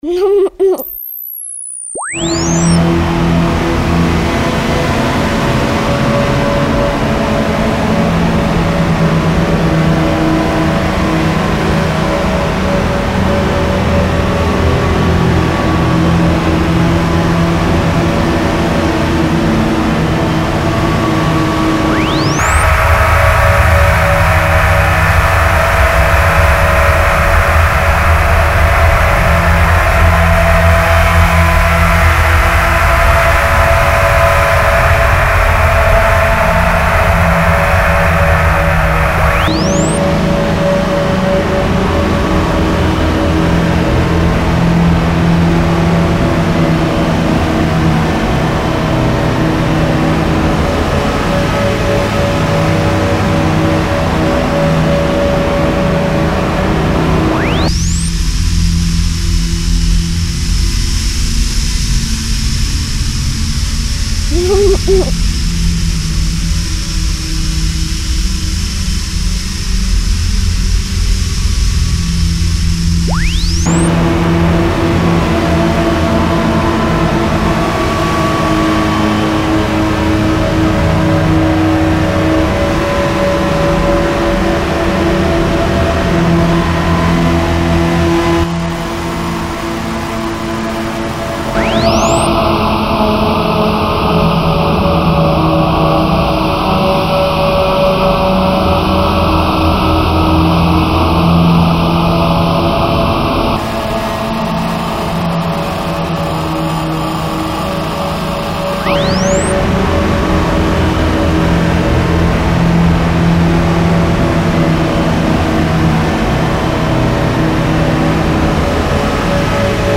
File under: Infected Ambient